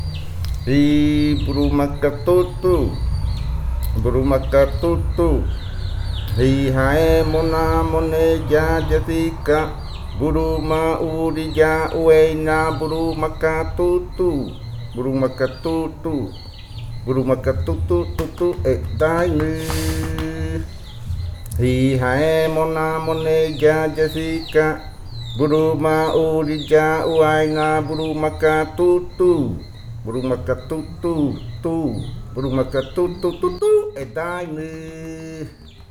Leticia, Amazonas, (Colombia)
Grupo de danza Kaɨ Komuiya Uai
Canto fakariya de la variante Muruikɨ (cantos de la parte de arriba) Esta grabación hace parte de una colección resultante del trabajo de investigación propia del grupo de danza Kaɨ Komuiya Uai (Leticia) sobre flautas y cantos de fakariya.
Fakariya chant of the Muruikɨ variant (Upriver chants).